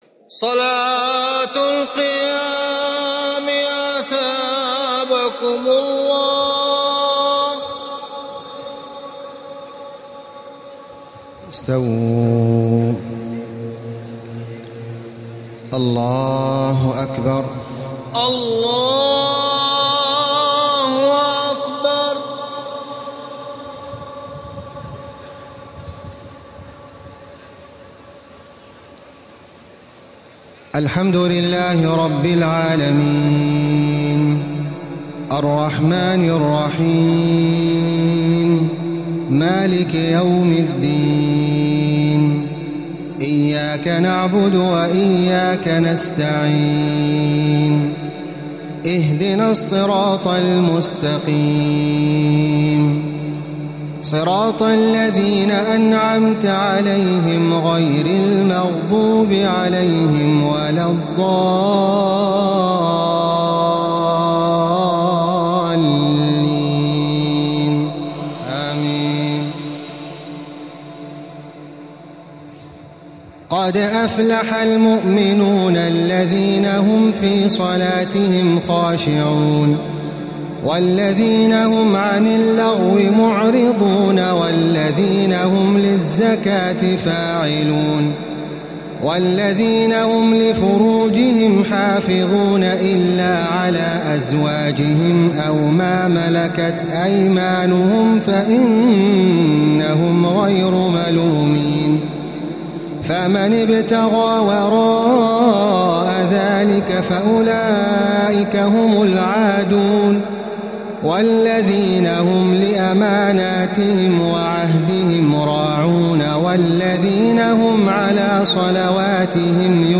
تراويح الليلة الثامنة عشر رمضان 1435هـ سورتي المؤمنون و النور (1-20) Taraweeh 18 st night Ramadan 1435H from Surah Al-Muminoon and An-Noor > تراويح الحرم المكي عام 1435 🕋 > التراويح - تلاوات الحرمين